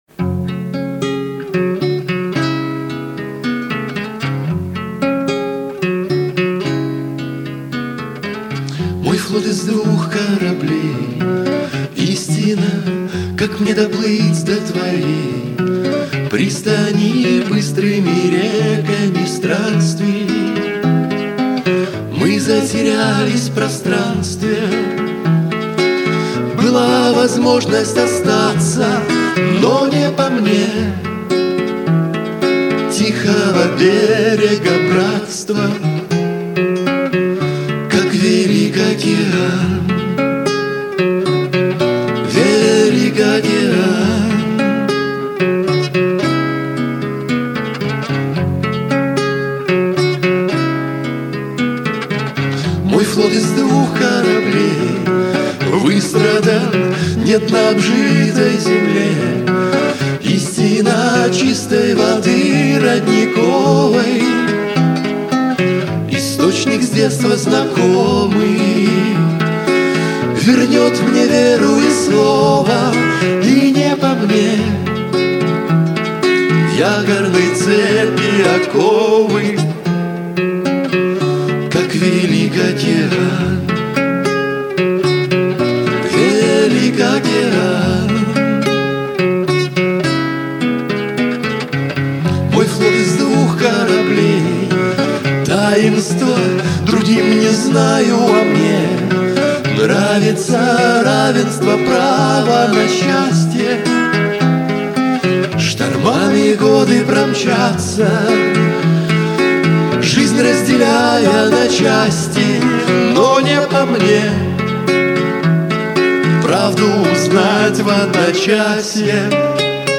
В исполнении авторов